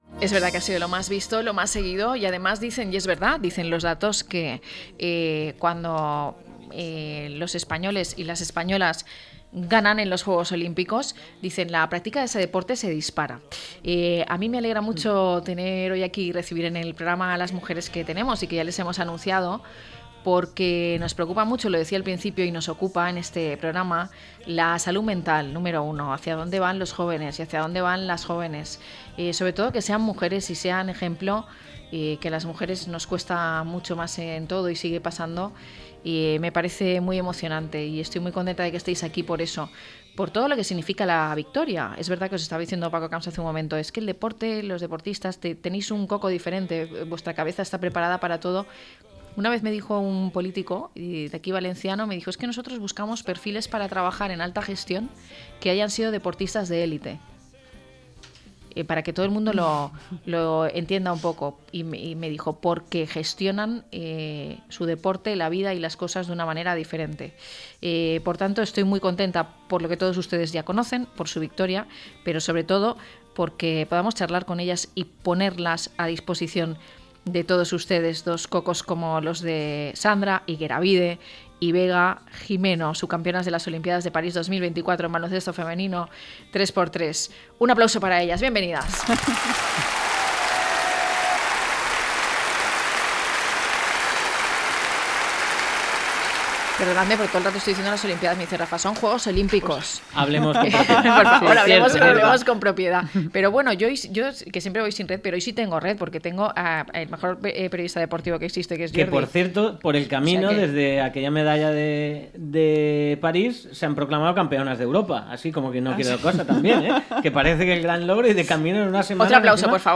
Recibimos en nuestros estudios a las subcampeonas de las Olimpiadas de París 2024 en Baloncesto femenino 3×3, las valencianas Sandra Ygueravide y Vega Gimeno.